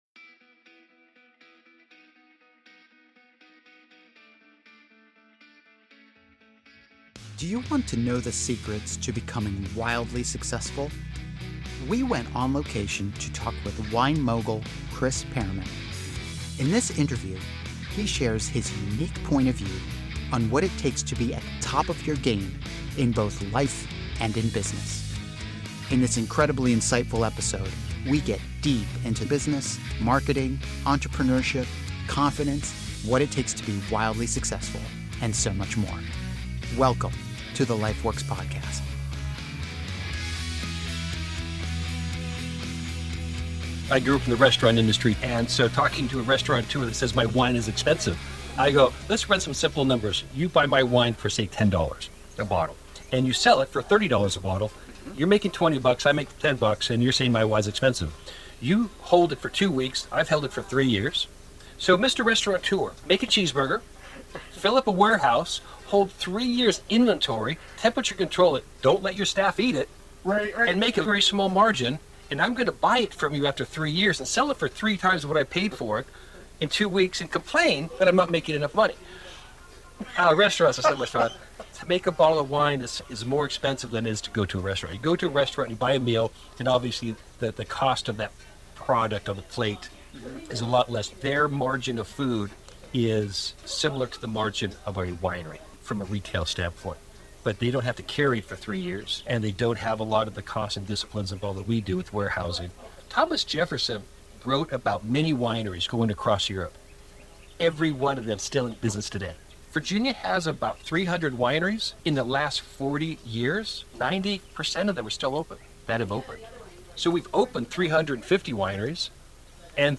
We went on location to talk with wine mogul